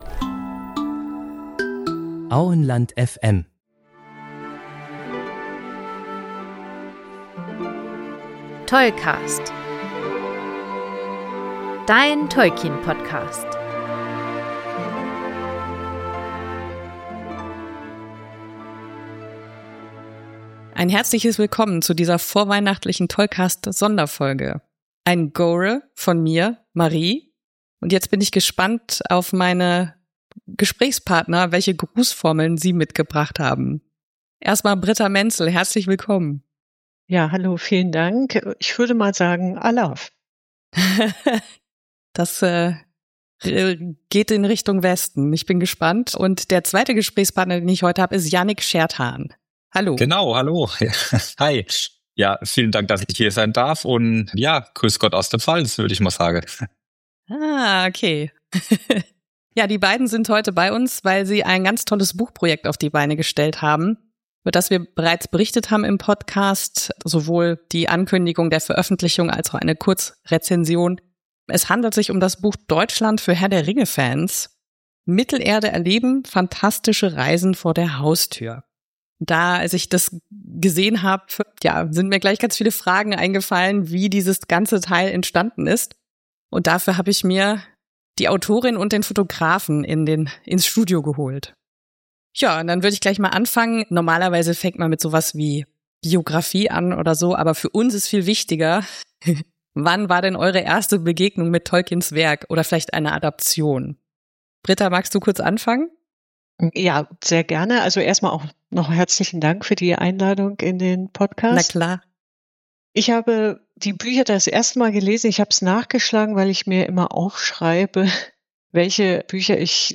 Interview zu Deutschland für Herr der Ringe Fans